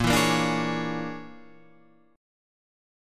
Bb7#9b5 chord